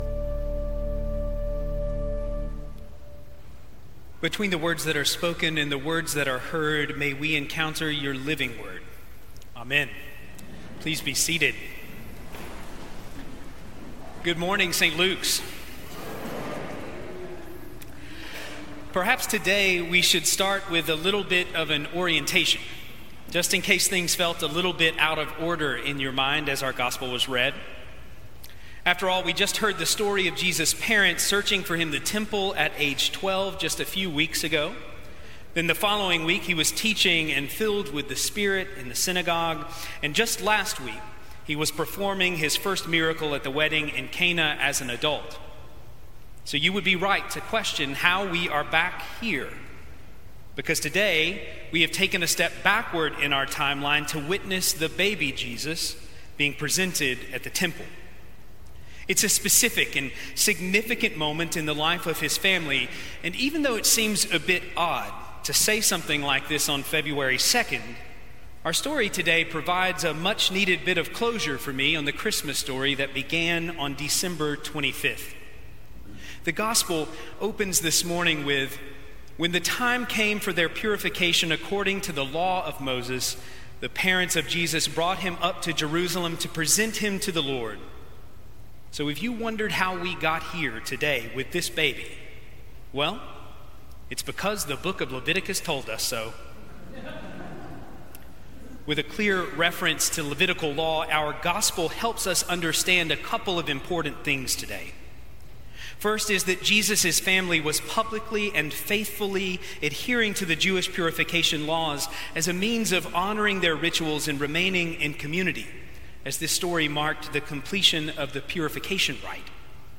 Sermons from St. Luke's Episcopal Church in Atlanta